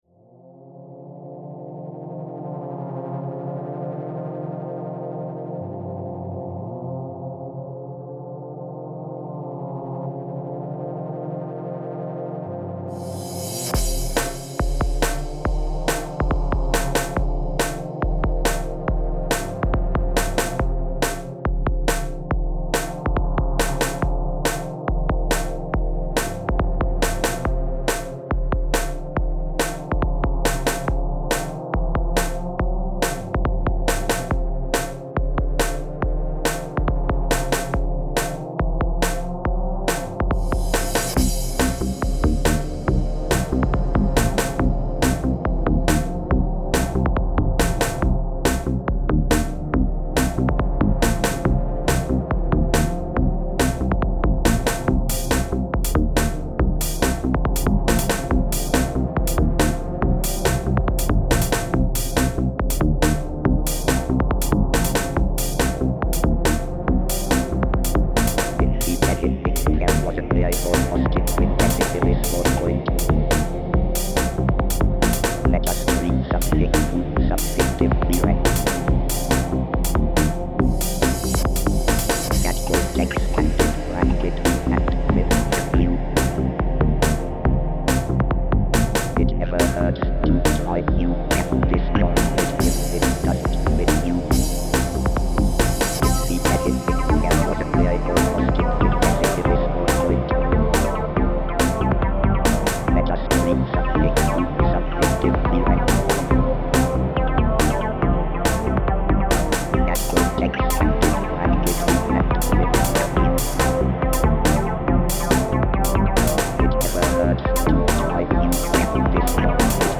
Electronic Music
Something New, Something Different 06:12 This was, as the title suggests, something a little different to the trance-type stuff I had been making.
Clearly my right-brain took control here :-) Composed in MULAB with a variety of VSTs and VSTis.